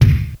7_crusty-kick-1.wav